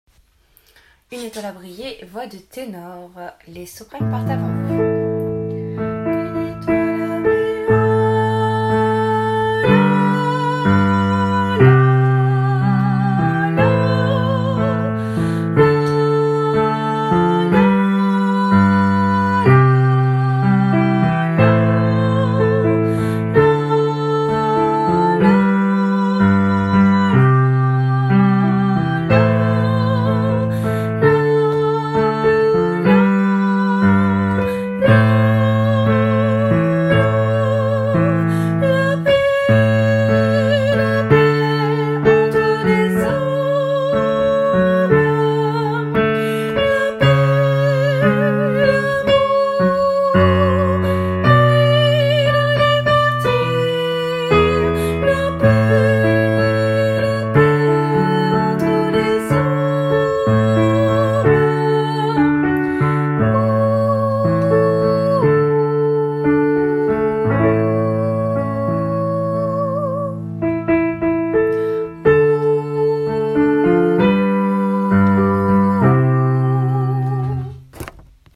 263-Une-Étoile-a-brille-ténor.mp3